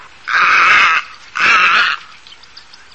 Garzetta – Garzèta
Egretta garzetta
Nella colonia, un gracchiante ‘gaaar’; generalmente silenzioso altrove.
Garzetta_Egretta_garzetta.mp3